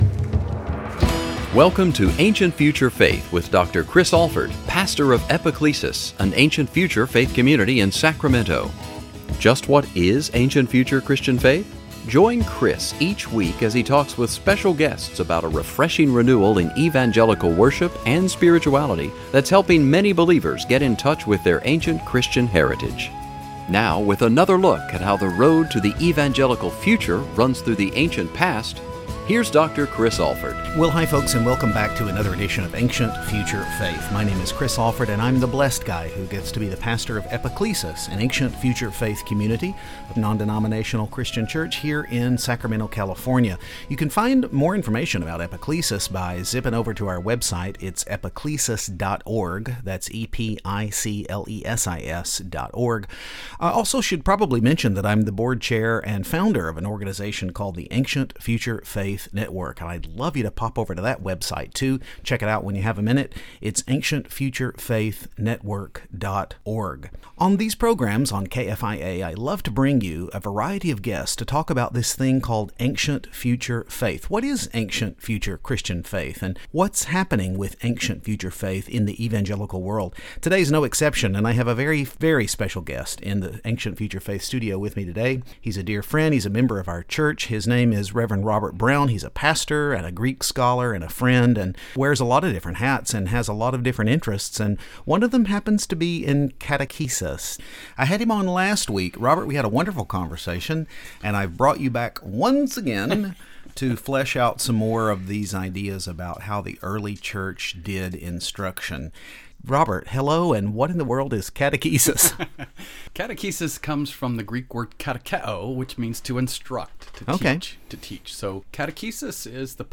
Ancient-Future Faith on KFIA